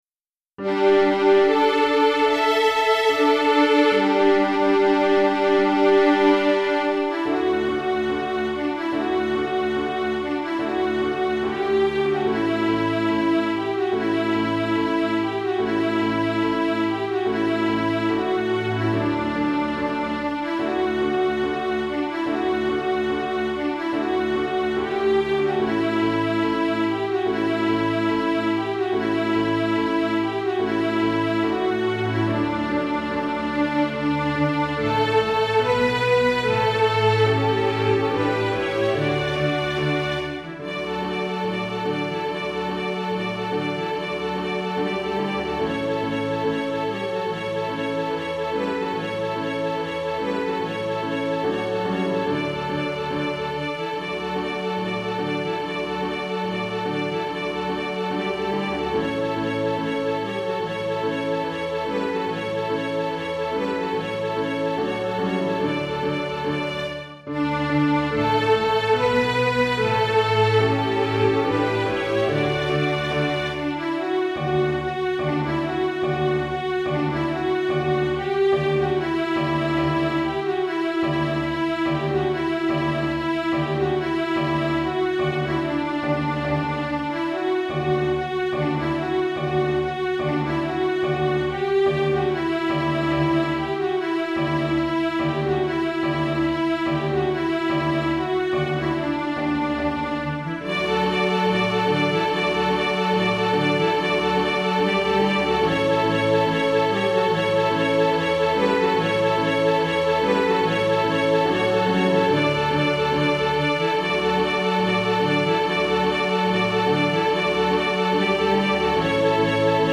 Répertoire pour Musique de chambre